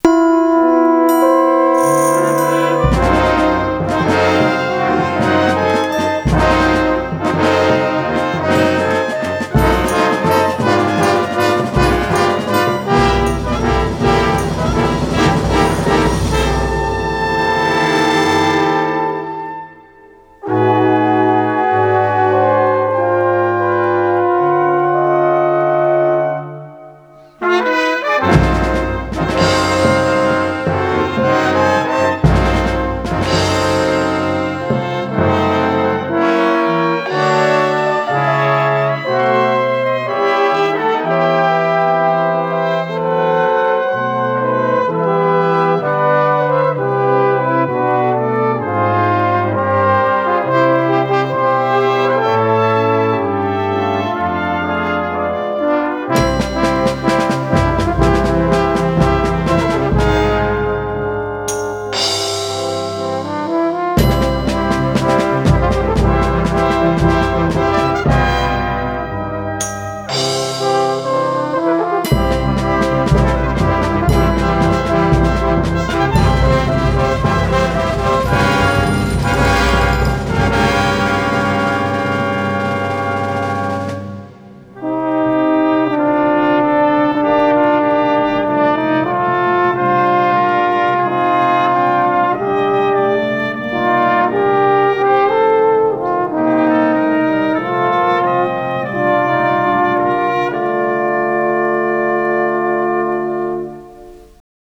Fanfarria de metales
aerófono
tambor
percusión
fanfarria
marcha
menor
solemne
sombrío
sordina